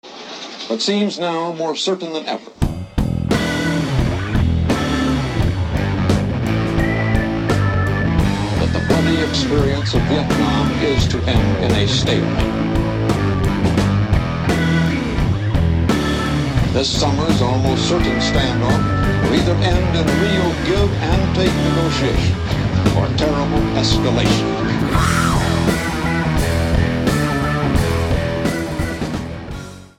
Jamband
Psychedelic
Rock